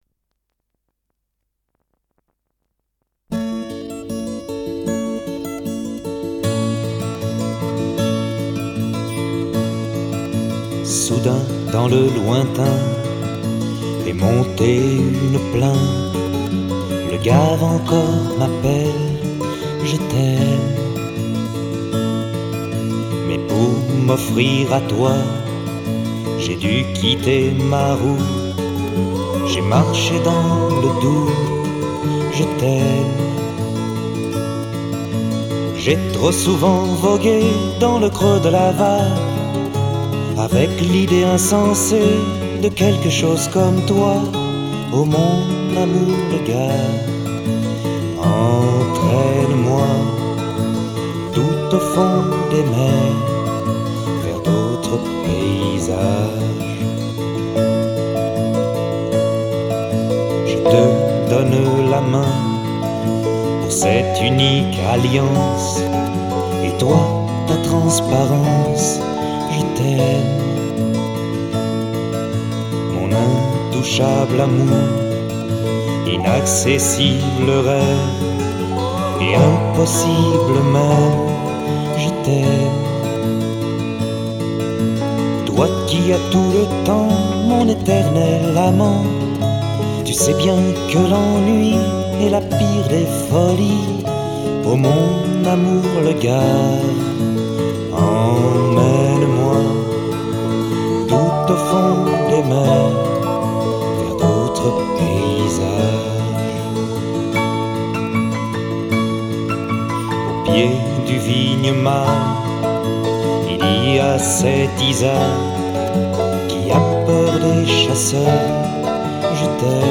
Guitares
Choriste
Synthé, piano électrique